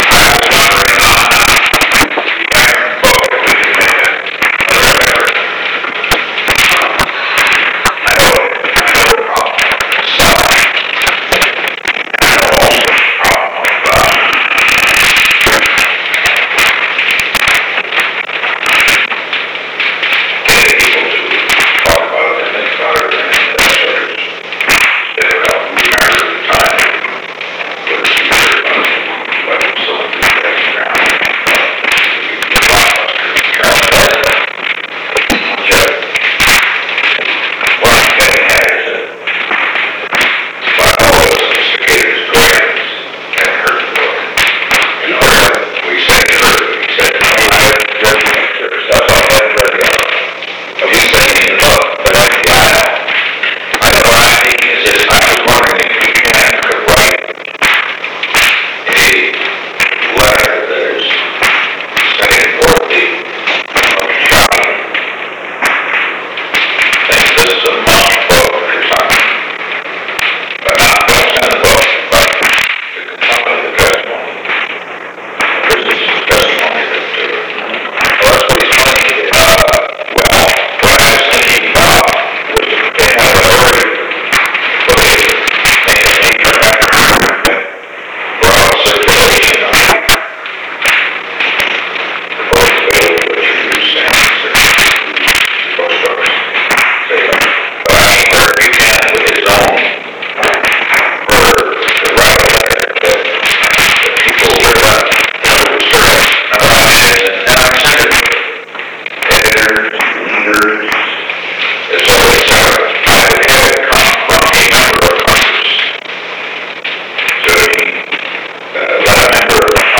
Conversation No. 663-3 Date: February 1, 1972 Time: 2:53 pm - 3:02 pm Location: Oval Office The President met with H. R. (“Bob”) Haldeman.
Secret White House Tapes